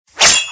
player_sword.ogg